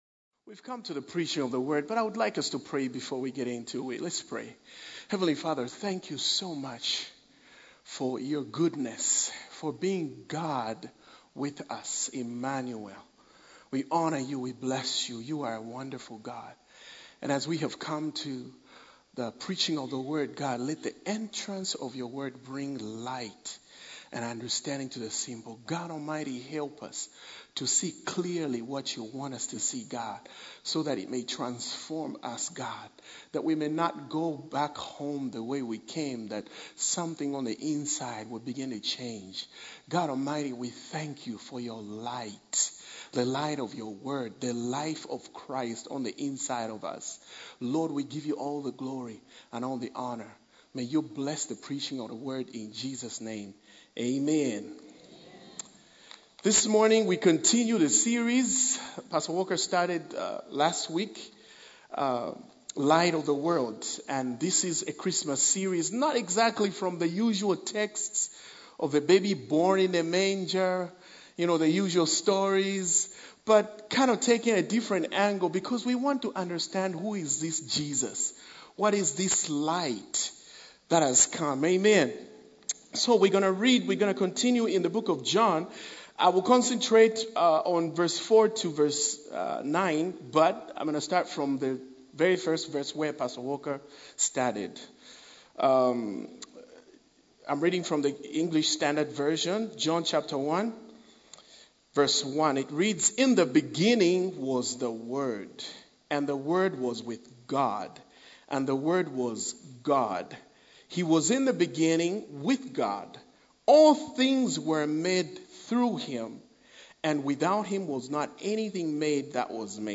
A message from the series "The Light of the World."